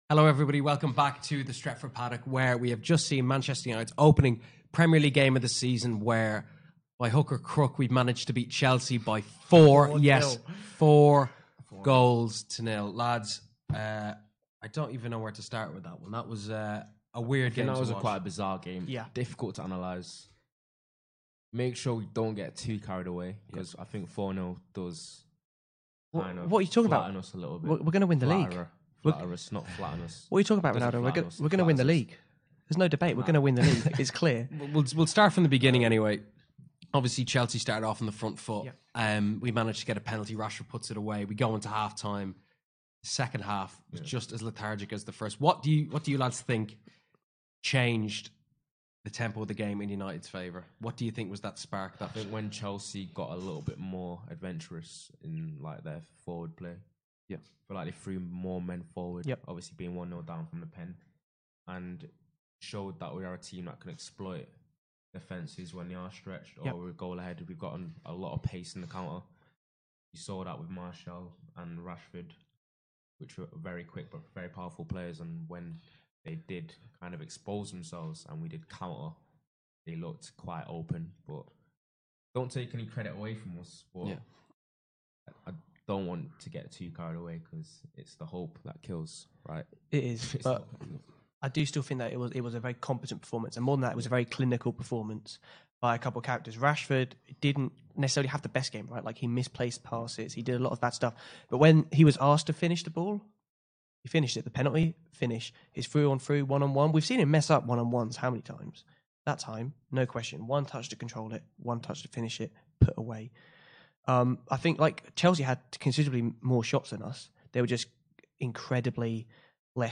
Headliner Embed Embed code See more options Share Facebook X Subscribe Manchester United 4-0 Chelsea Here's the immediate reaction from the lads in the studio.